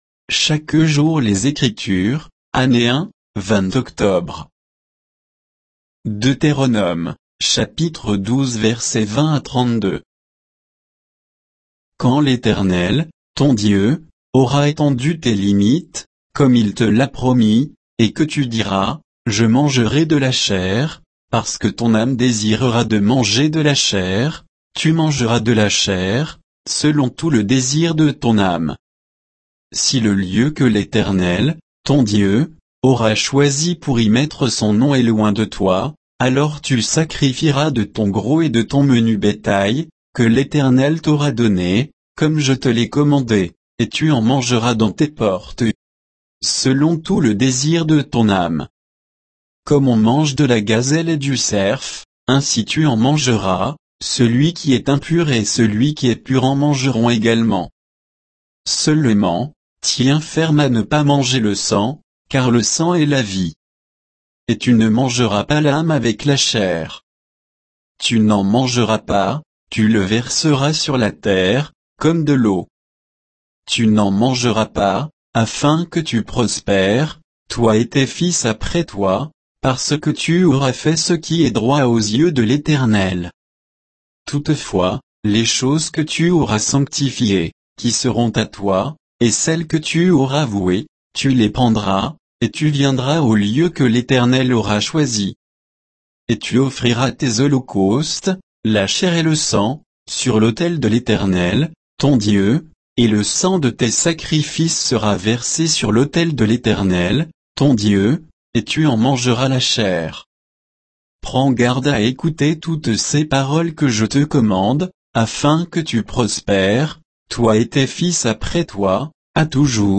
Méditation quoditienne de Chaque jour les Écritures sur Deutéronome 12, 20 à 32